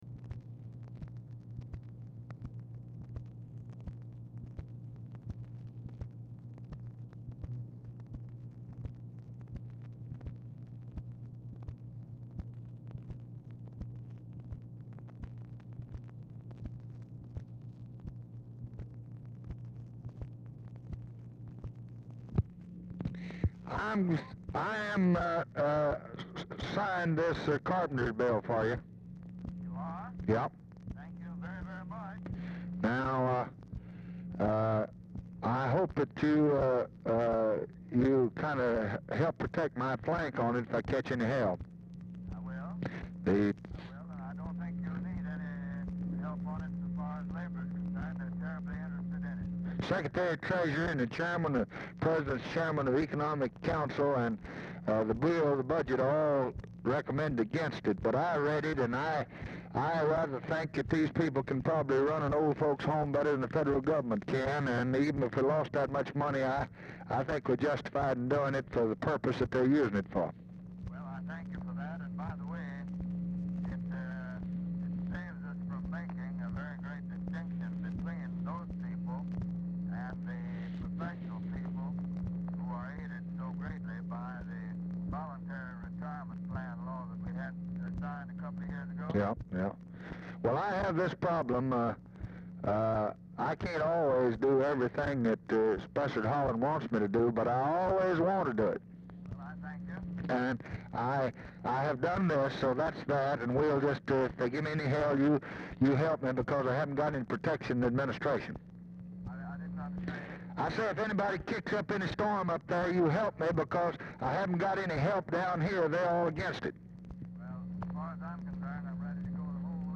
Telephone conversation # 4264, sound recording, LBJ and SPESSARD HOLLAND, 7/17/1964, 1:50PM
POOR SOUND QUALITY; HOLLAND IS DIFFICULT TO HEAR
Format Dictation belt